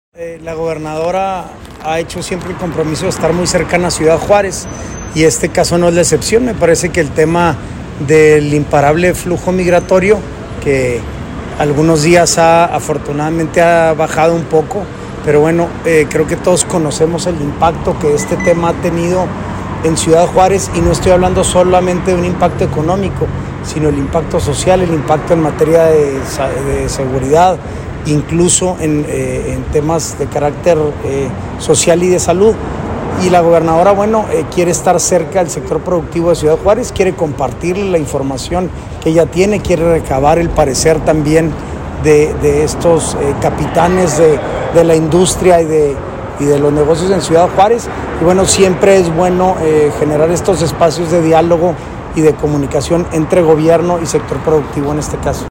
AUDIO: SANTIAGO DE LA PEÑA, SECRETARÍA GENERAL DE GOBIERNO (SGG)